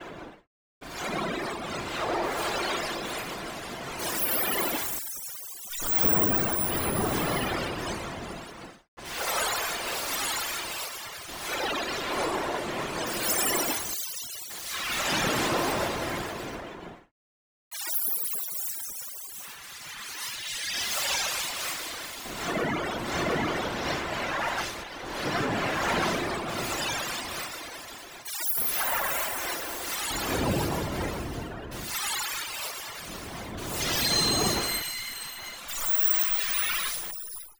Interactive sound installation
time_travel1.wav